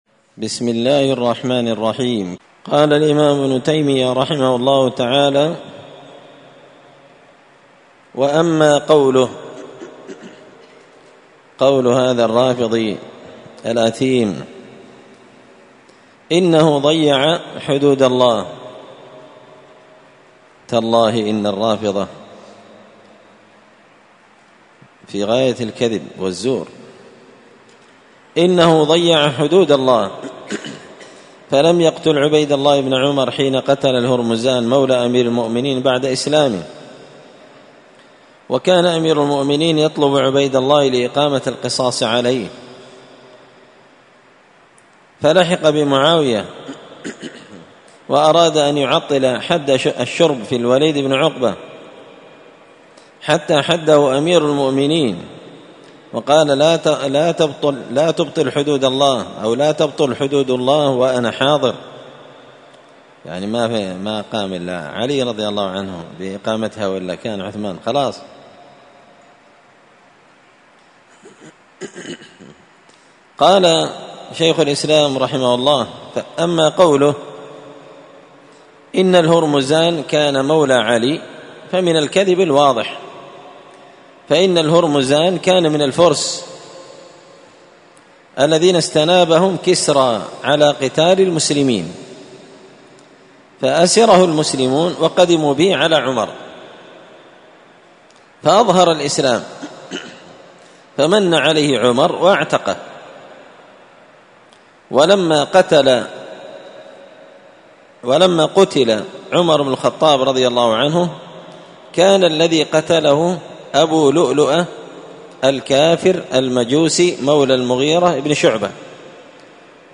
الخميس 25 ذو الحجة 1444 هــــ | الدروس، دروس الردود، مختصر منهاج السنة النبوية لشيخ الإسلام ابن تيمية | شارك بتعليقك | 6 المشاهدات